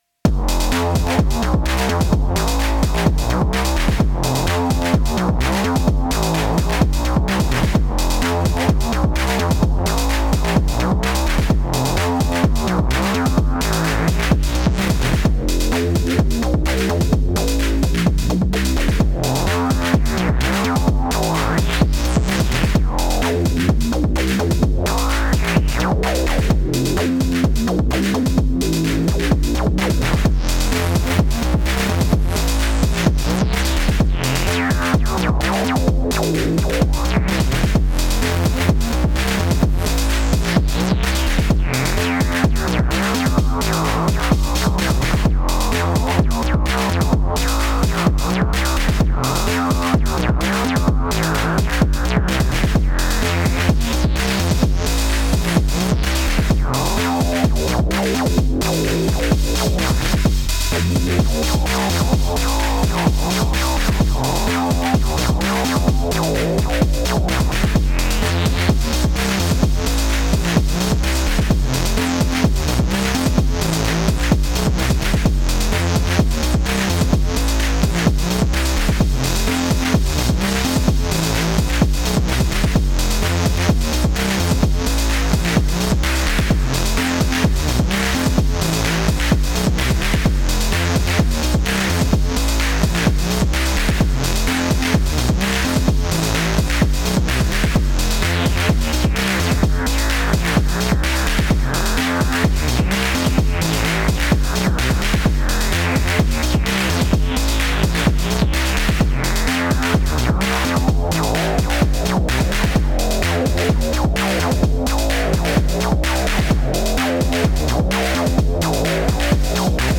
Techno System + AH (bass melody borrowed)